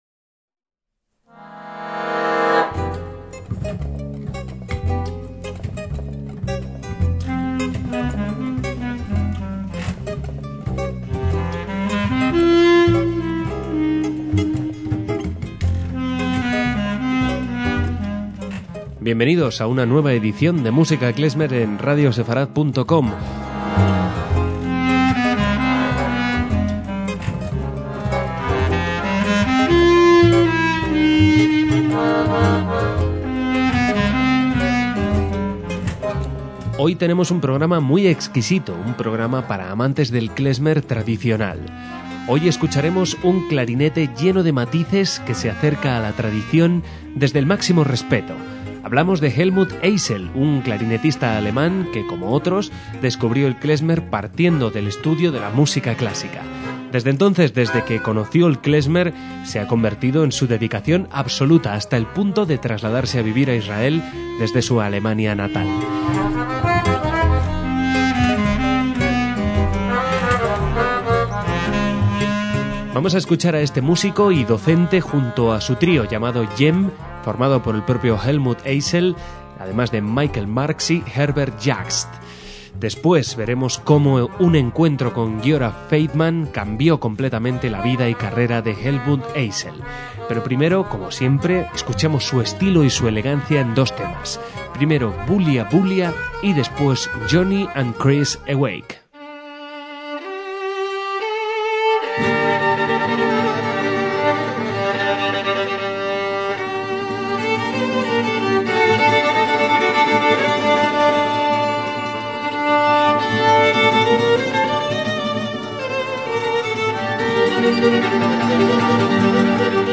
guitarra
bajista